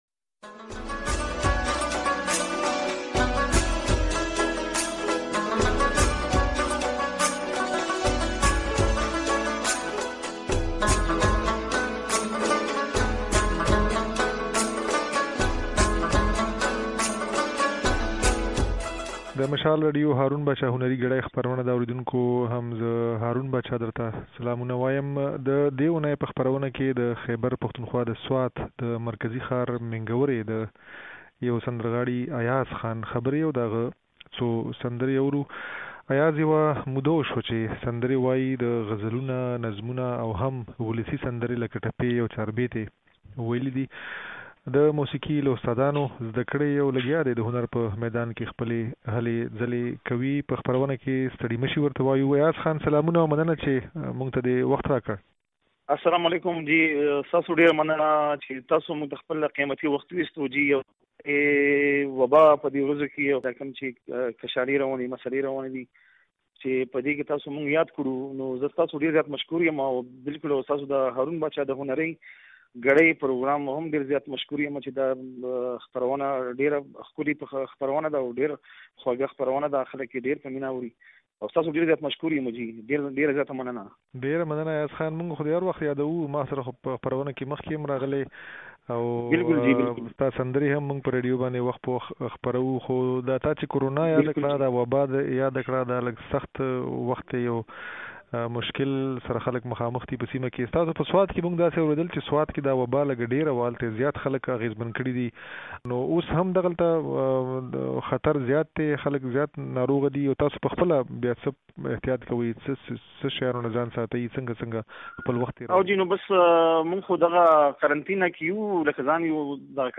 يو ځوان سندرغاړی